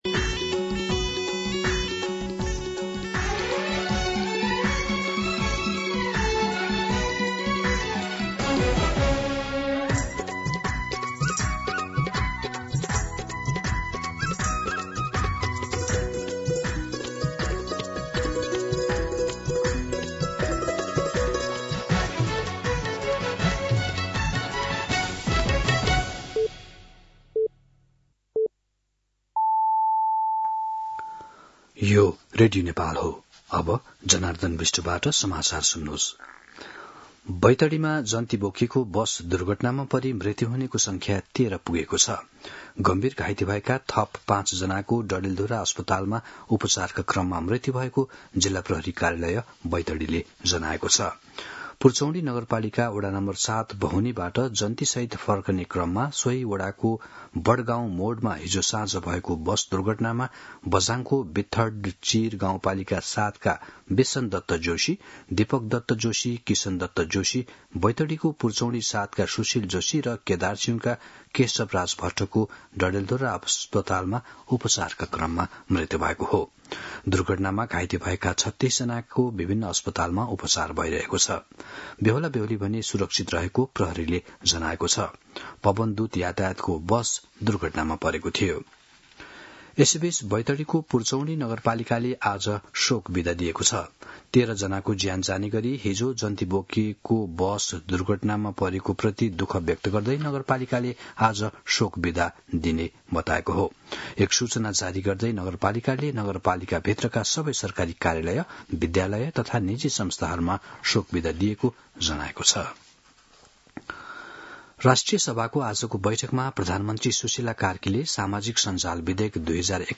मध्यान्ह १२ बजेको नेपाली समाचार : २३ माघ , २०८२